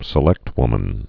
(sĭ-lĕktwmən)